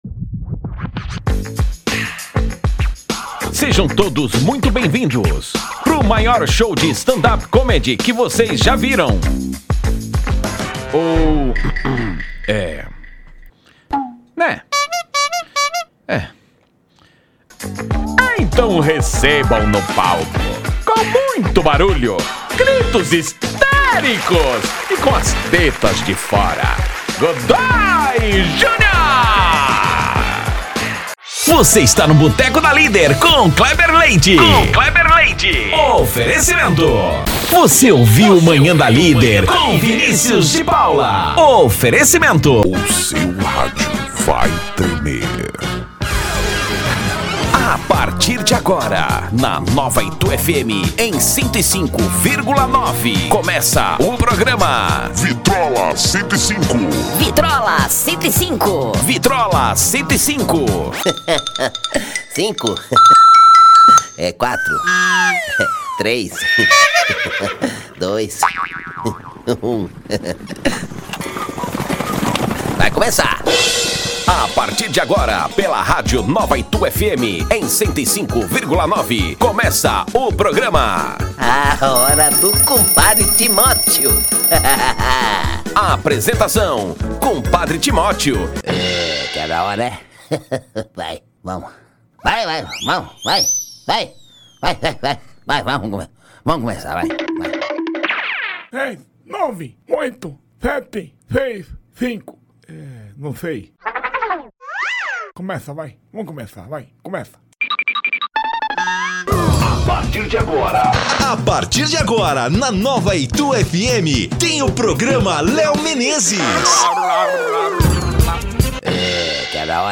DEMONSTRATIVO ABERTURAS, CHAMADAS, VINHETAS PARA RADIO, TV E INTERNET:
Impacto